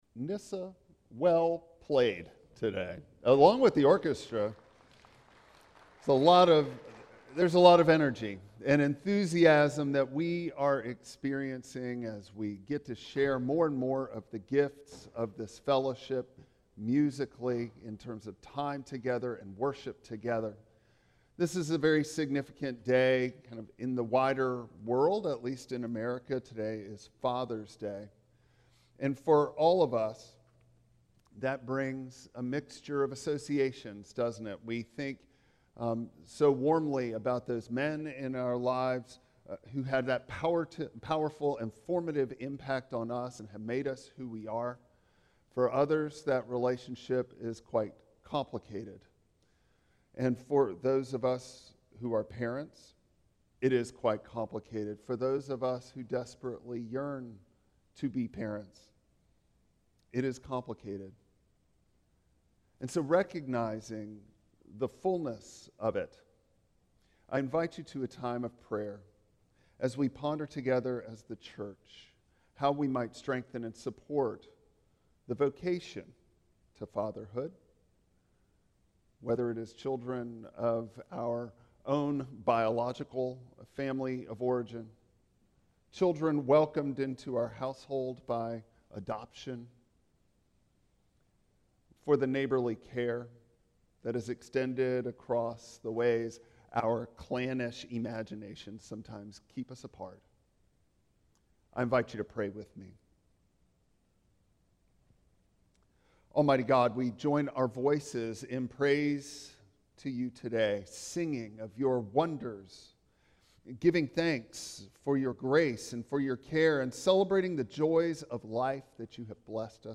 Passage: Nehemiah 2:1-10 Service Type: Traditional Service